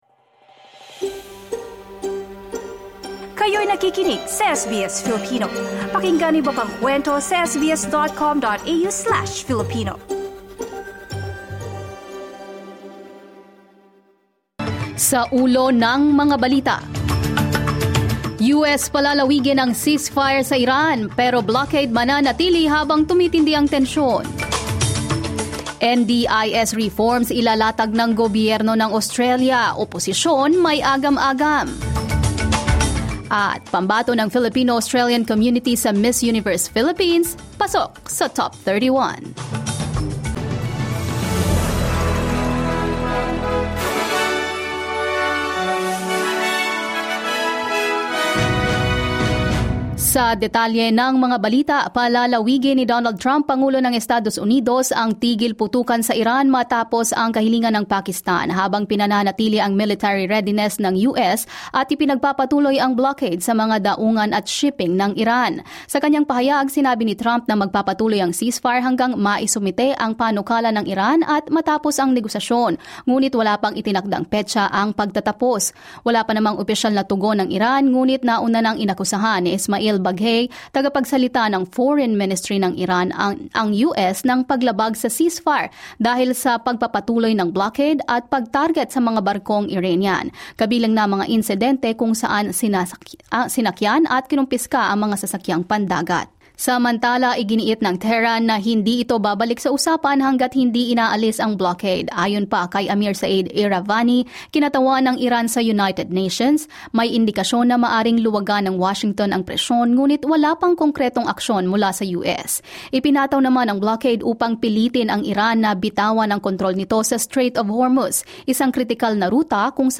SBS News in Filipino, Wednesday 22 April 2026